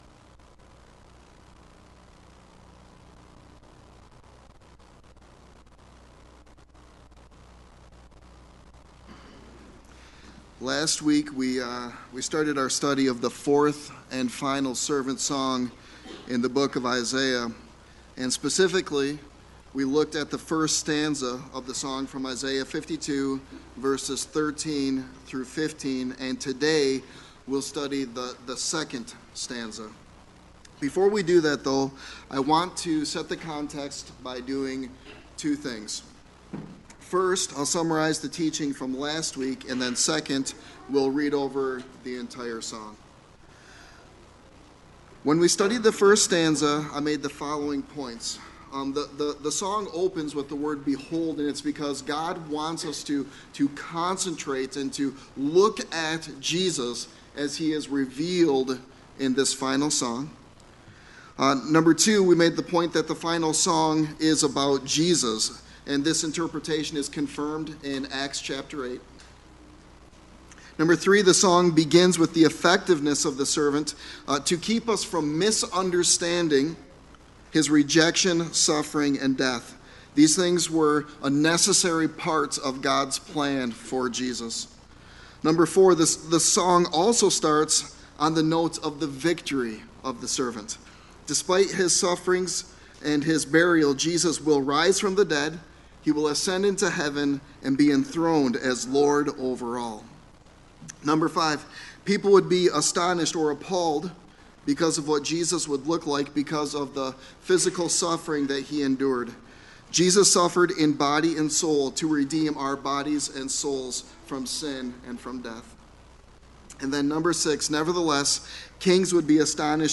Sermon Text: Isaiah 53:1-3